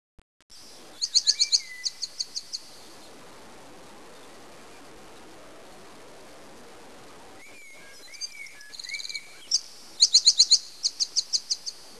Database dei canti ed altre vocalizzazioni
Il canto di.... pro.wav